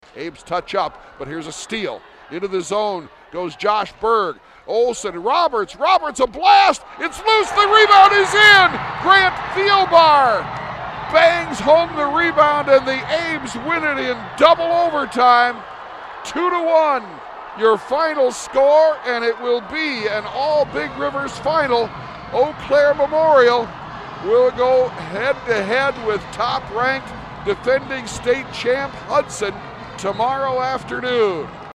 This was a WIAA State Tournament Semifinal Game played at the Alliant Energy Center